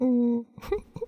SFX害羞2音效下载
SFX音效